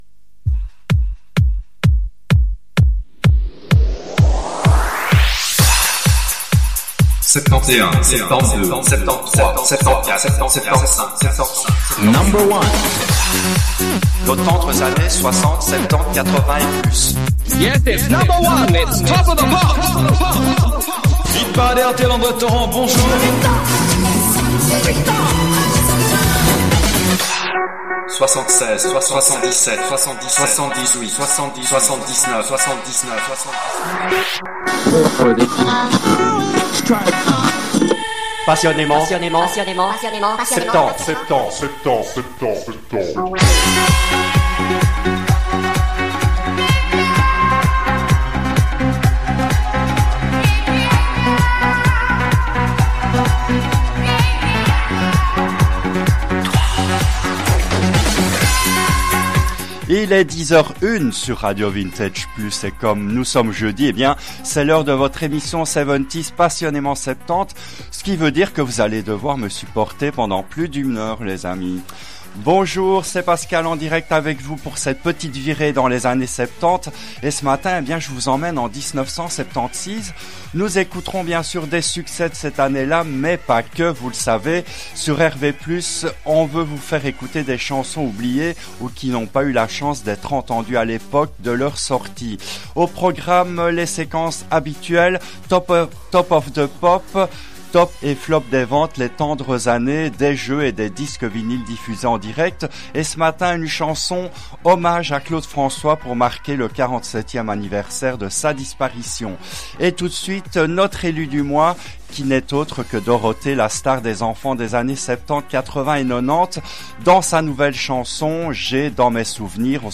L’émission a été diffusée en direct le jeudi 13 mars 2025 à 10h depuis les studios belges de RADIO RV+